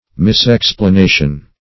Misexplanation \Mis*ex`pla*na"tion\, n. An erroneous explanation.